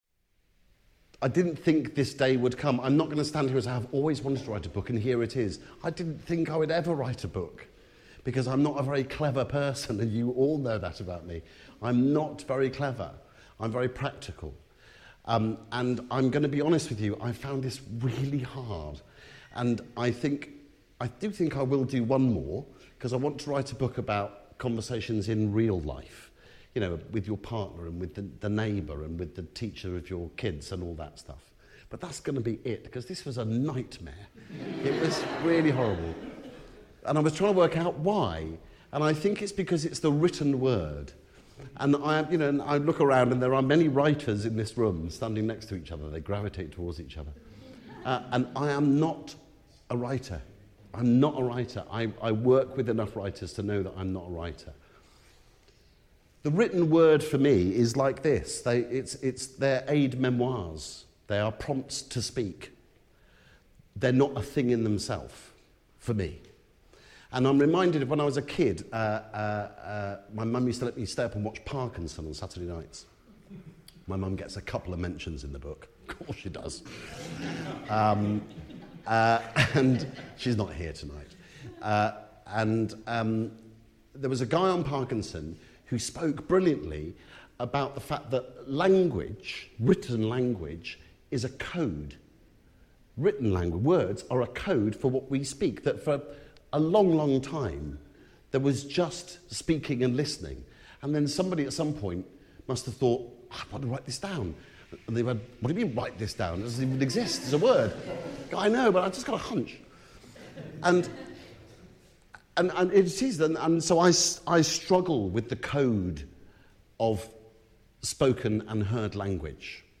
speech at the launch at Somerset House